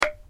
Звуки ложки
Звук удара деревянной ложкой о стол